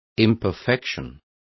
Also find out how desperfecto is pronounced correctly.